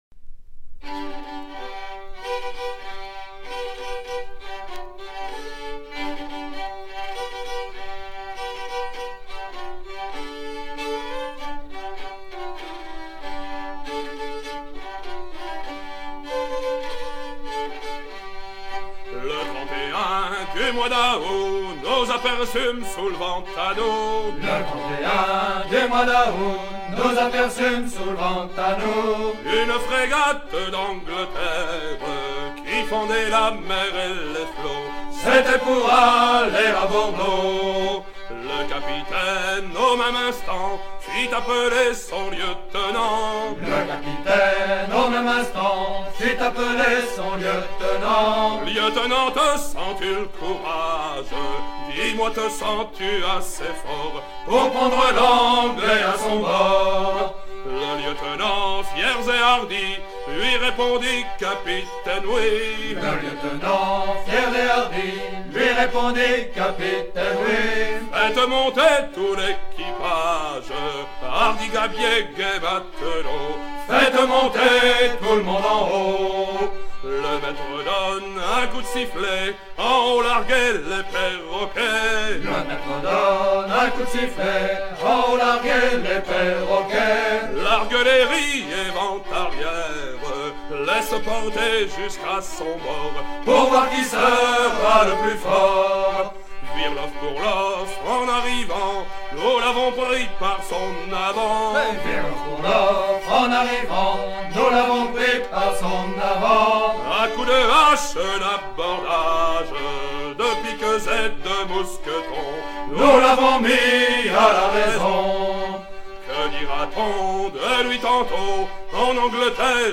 Genre strophique